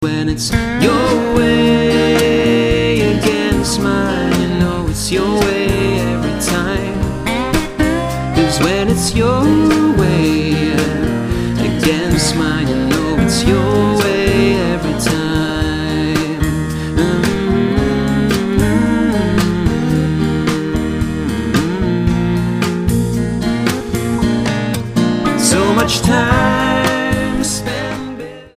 STYLE: Pop
It's all very pleasant but unfortunately not very memorable.